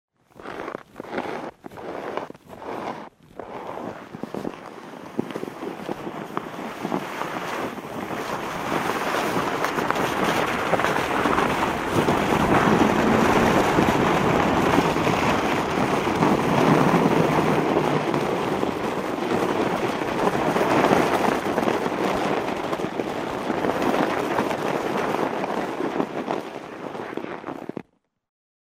Звуки санок